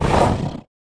Index of /App/sound/monster/ice_snow_dog
damage_1.wav